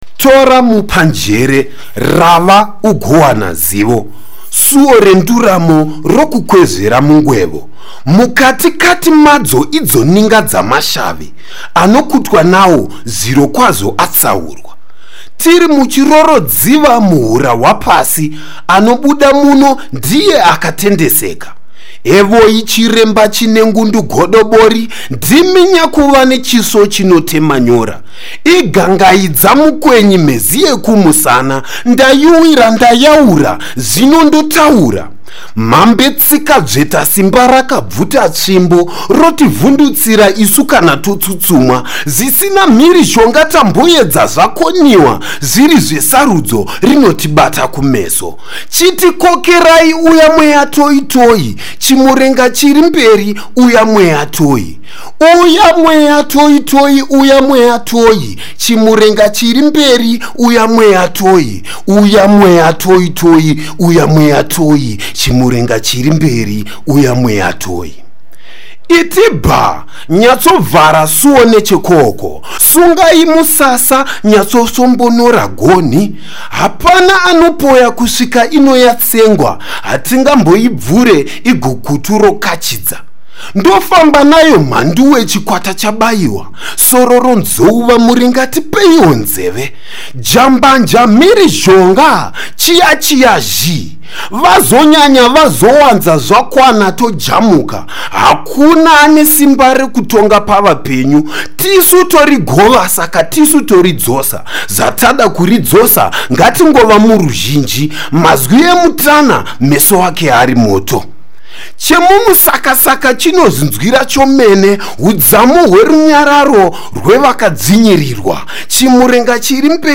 This a voice asking for support from the spiritual world.